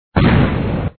explo.mp3